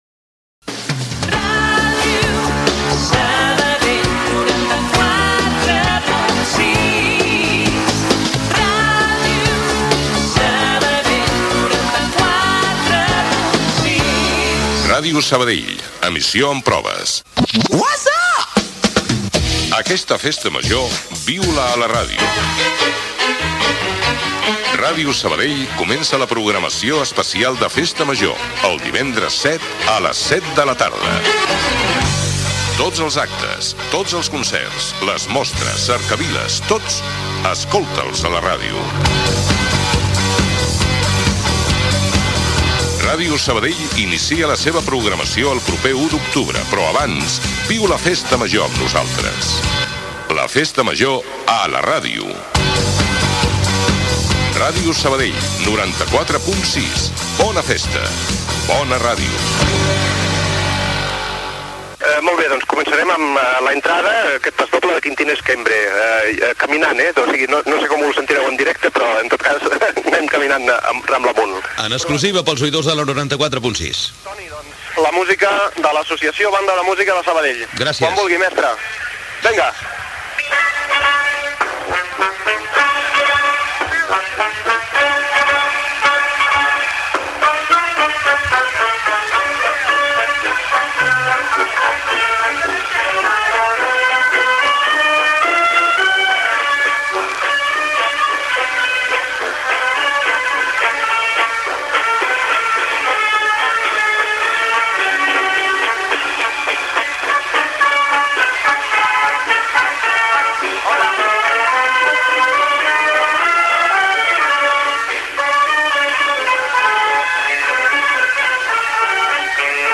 Indicatiu de la ràdio, anunci de l'emissió en proves durant la festa major (veu: Constantino Romero), connexió amb la primera cercavila de Festa Major: l'Associació Banda de Música de Sabadell i els gegants i capgrossos. Entrevista a Sergi Mas que farà el pregó de Festa Major
Entreteniment